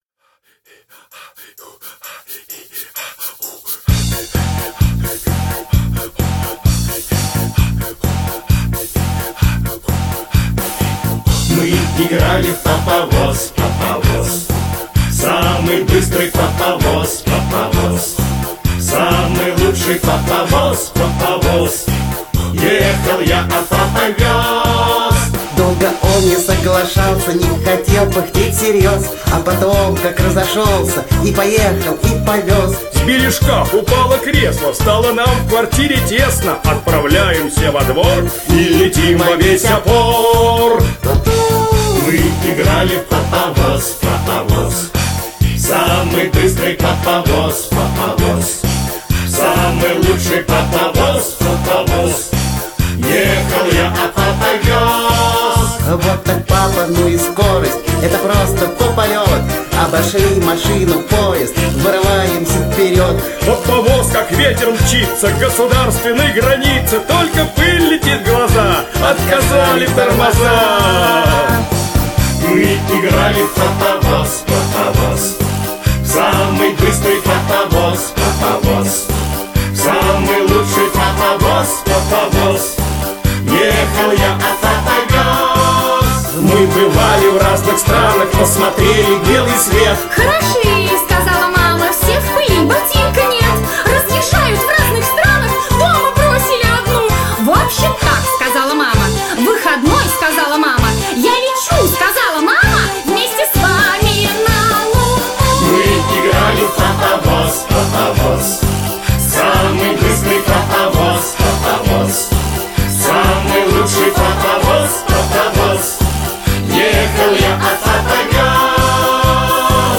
Замечательная песенка-игра.